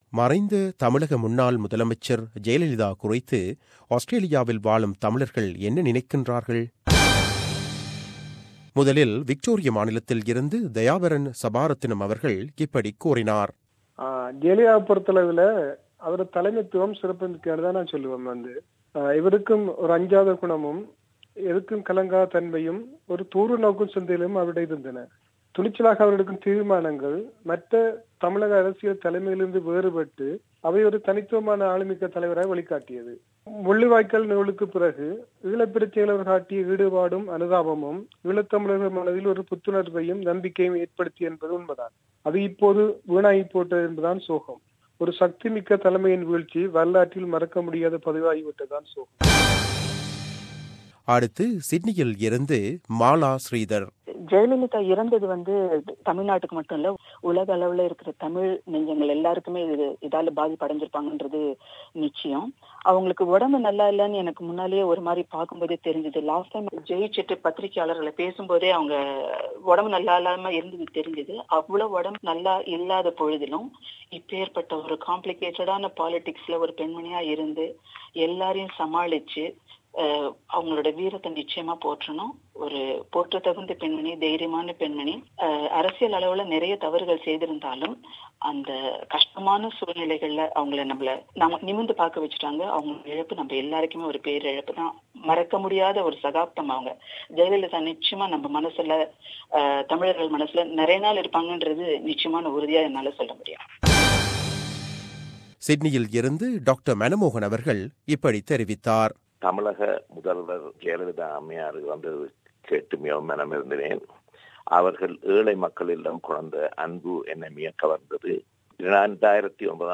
VoxPop : Late CM Jayalalitha